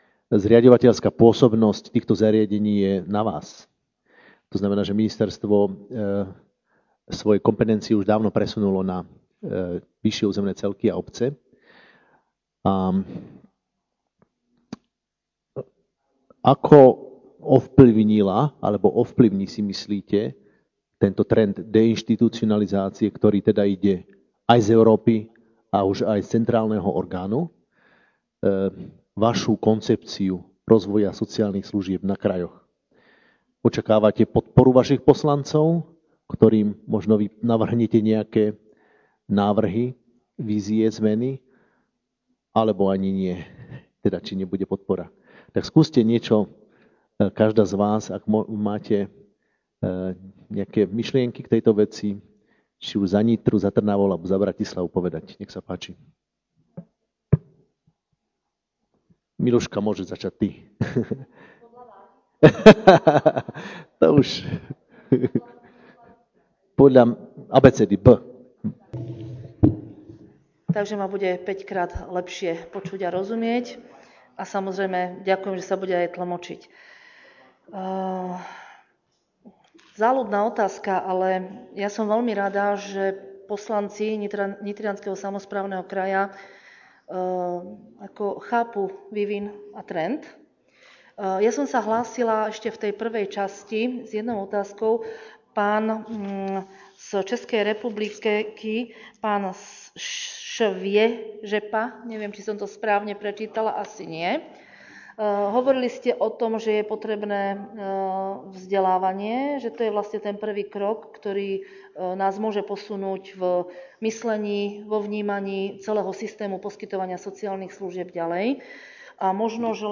nahrávka diskusie po zahraničných príspevkoch (mix jazykov)
panelova_diskusia.mp3